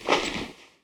equip_generic1.ogg